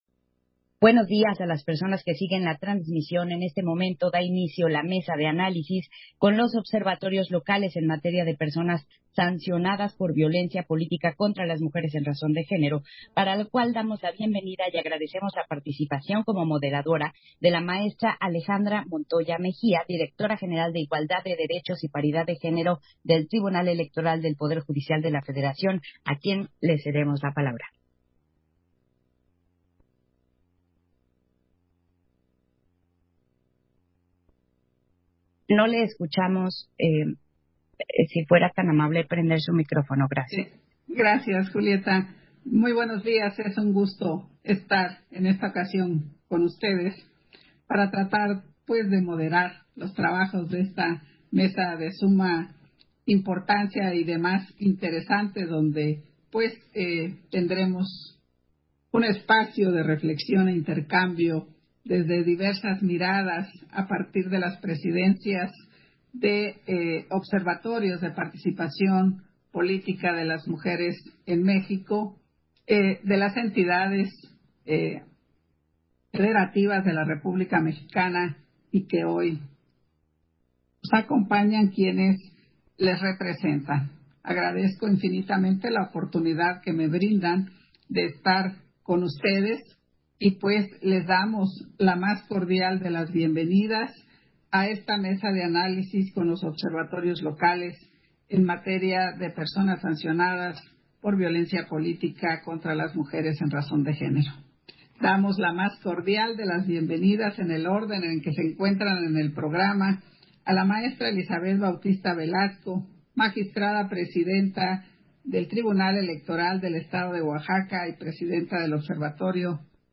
Mesa de análisis con observatorios locales en materia de personas sancionadas por violencia política contra las mujeres en razón de género. VI Encuentro Nacional de Observatorios Locales de Participación Política de las Mujeres